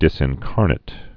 (dĭsĭn-kärnĭt)